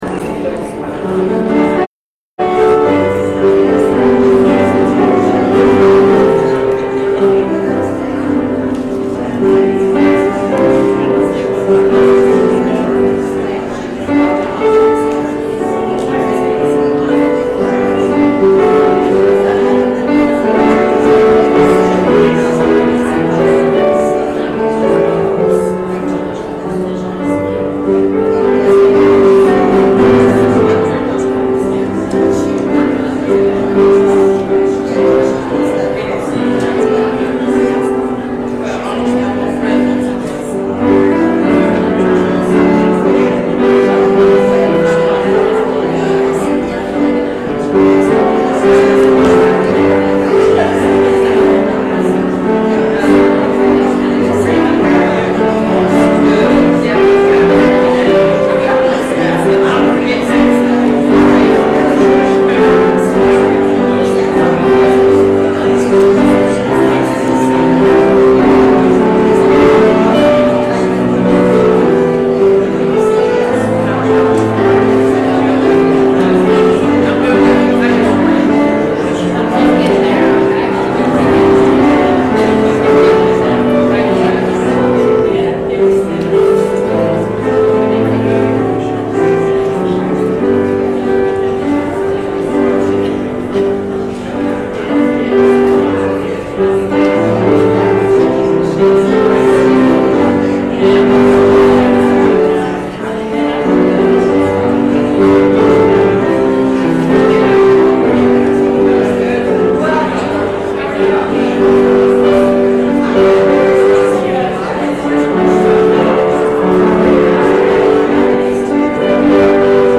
Passage: John 2:1-11 Service Type: Morning « How Come Nothing “Epiphs” on Epiphany Anymore?